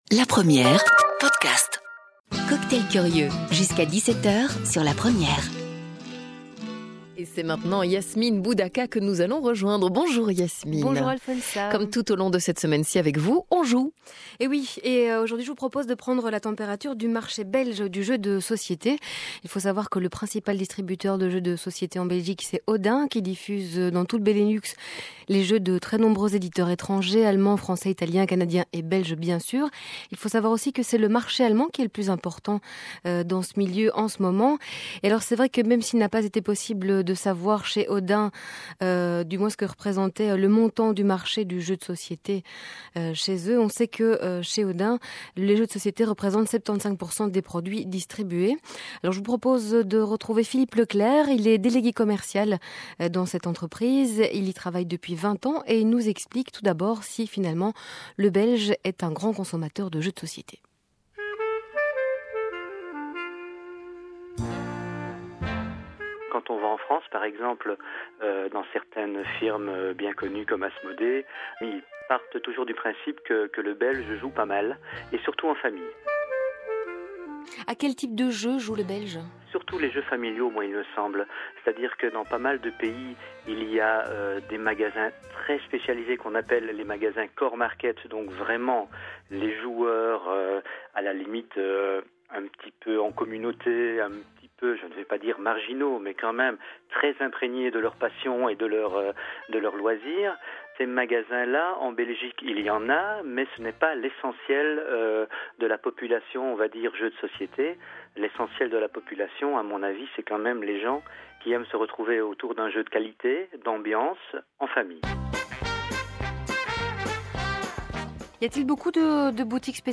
Le reportage en compagnie de de chez
(diffusé par La Première, mercredi 28 Janvier 2009 – Emission Cocktail Curieux)